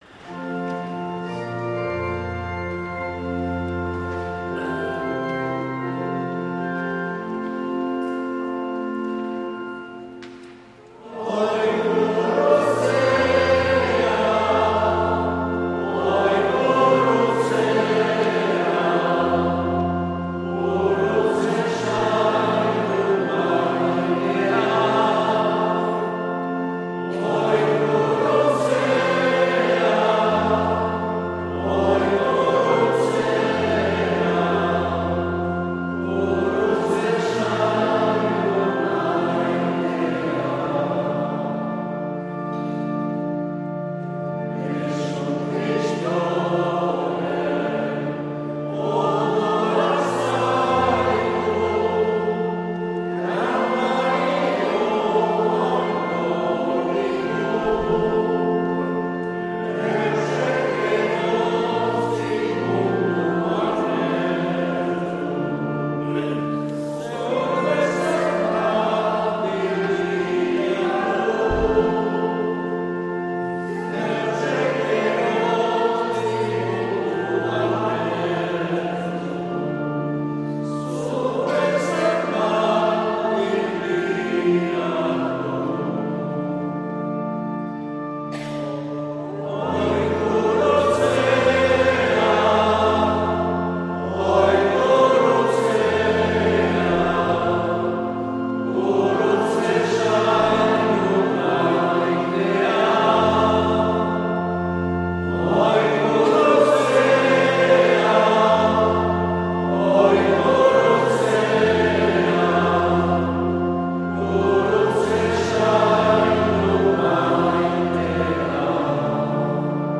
2025-09-14 Gurutze Sainduaren Gorestea - Donibane Garazi